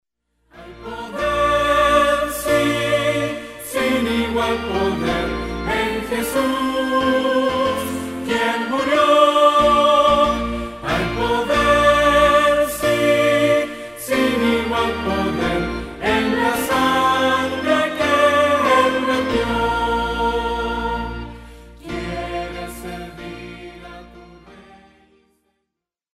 que presenta himnos tradicionales con un enfoque fresco